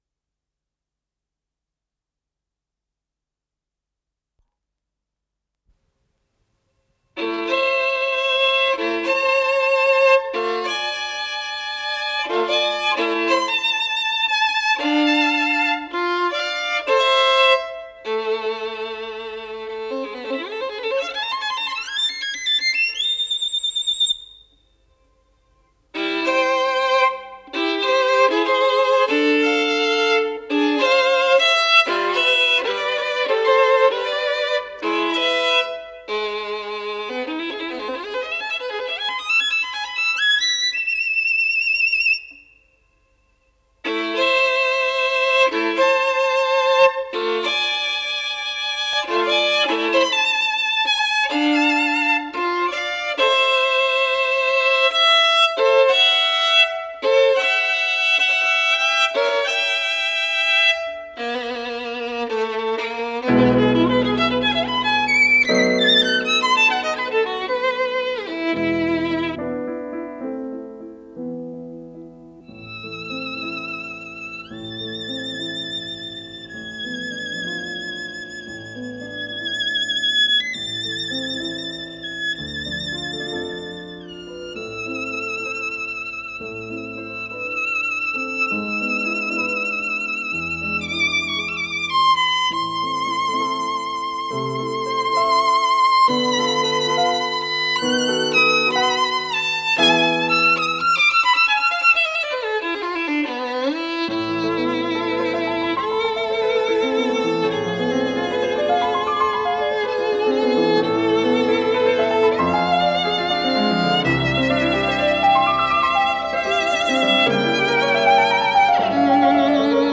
скрипка
фортепиано
Ля мажор